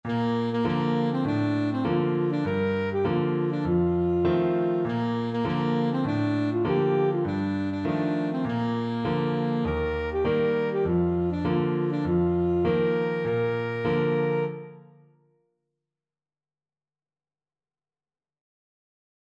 Alto Saxophone
Traditional Music of unknown author.
2/4 (View more 2/4 Music)
Playfully =c.100